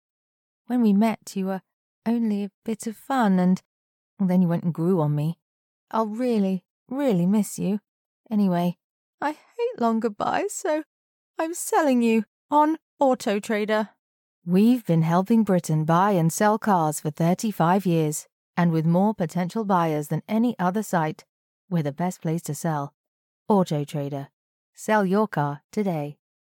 Female
Radio Commercials
British Advert I Autotrader
Words that describe my voice are Conversational, Believable, Engaging.
All our voice actors have professional broadcast quality recording studios.